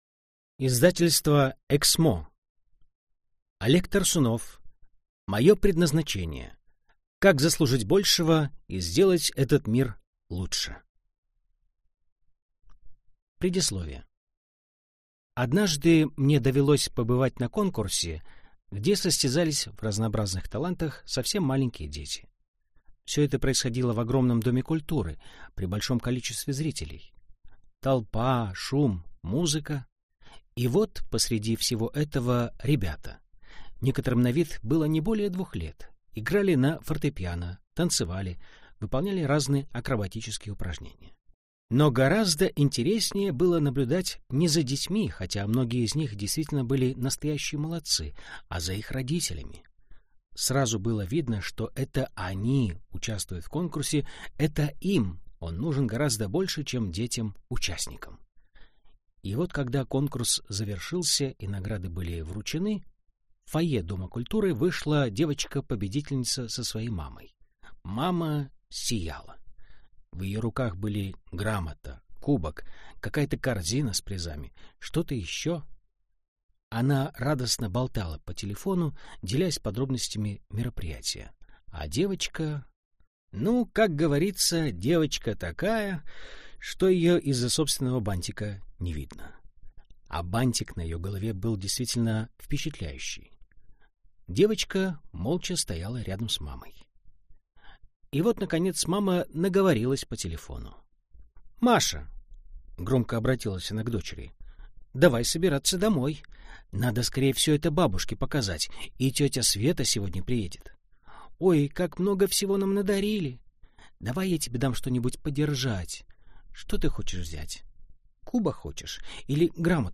Аудиокнига Мое предназначение. Как заслужить большего и сделать этот мир лучше | Библиотека аудиокниг